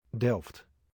Delft (/dɛlft/